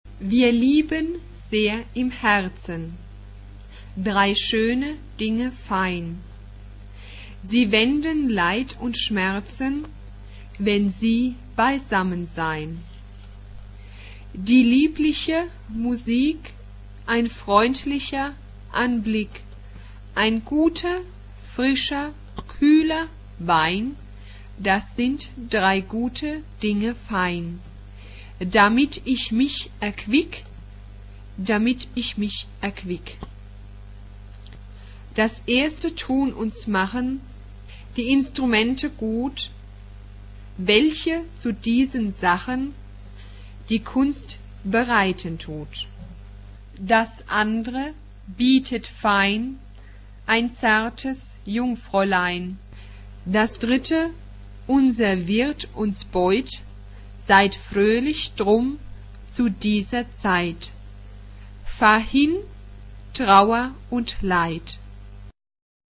Genre-Style-Forme : Profane ; Baroque ; Choral
Caractère de la pièce : vivant
Type de choeur : SATB  (4 voix mixtes )
Tonalité : sol majeur